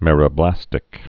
(mĕrə-blăstĭk)